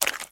STEPS Swamp, Walk 23.wav